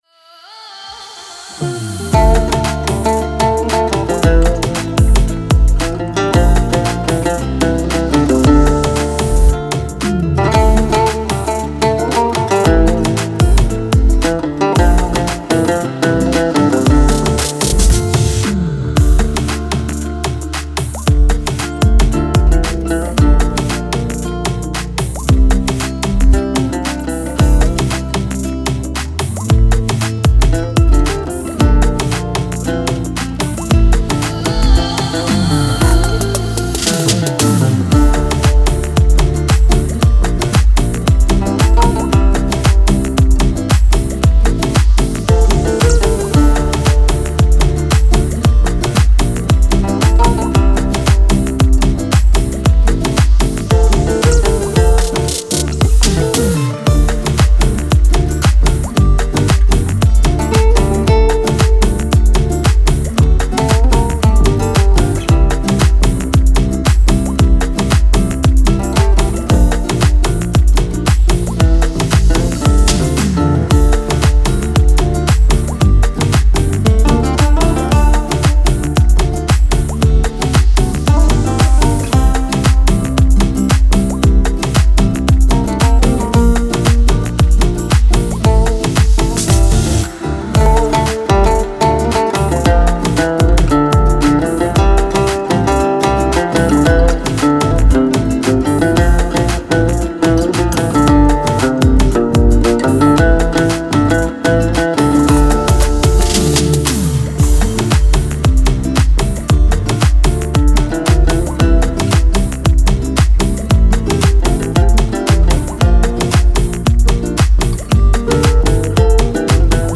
ضبط شده در: استودیو شقایق